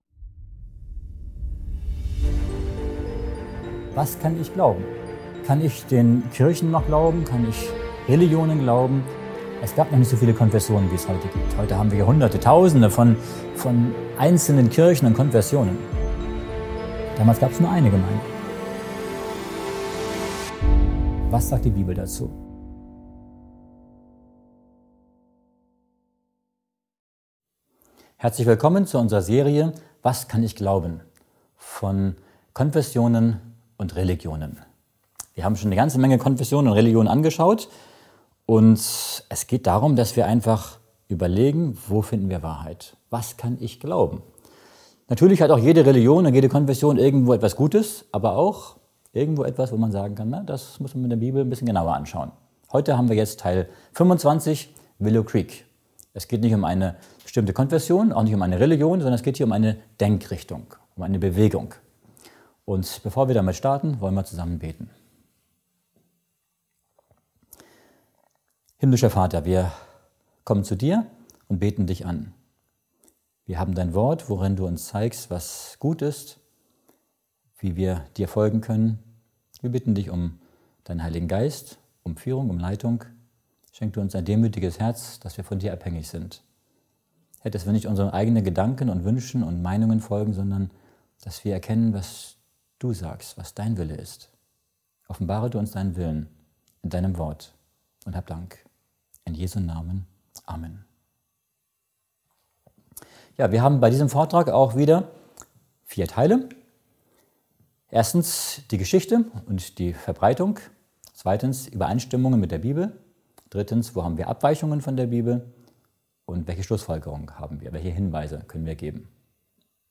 Der Vortrag beleuchtet biblische Wahrheiten und fragt, ob Anpassungen im Gottesdienst wirklich zur Erlösung führen können. Erkenntnis und Ermahnung stehen im Mittelpunkt.